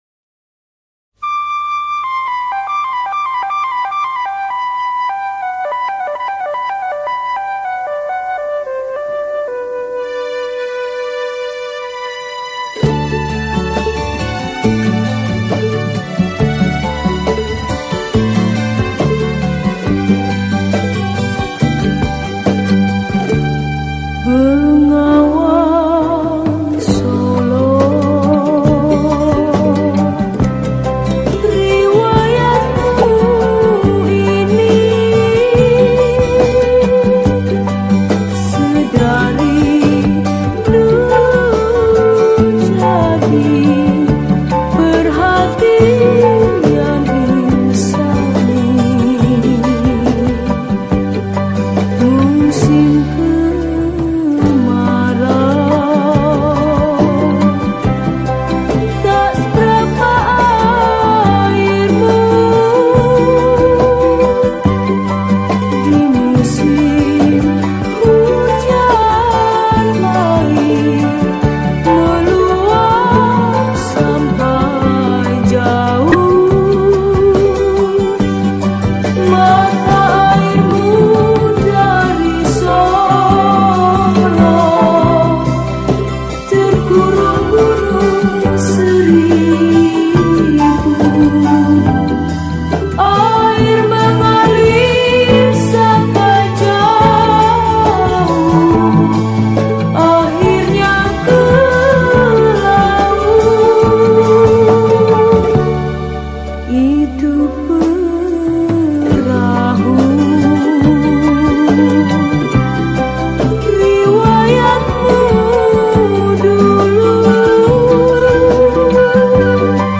Popular Old Indonesian Song Composed By